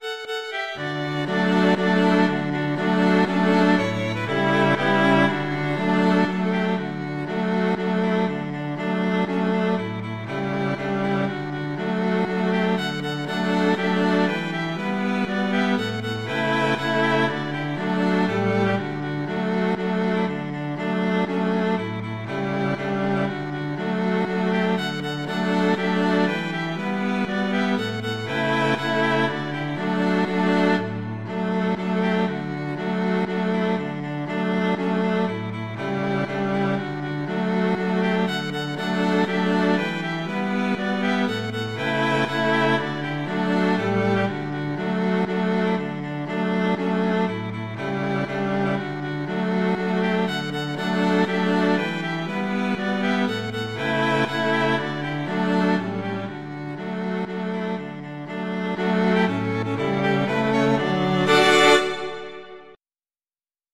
traditional, irish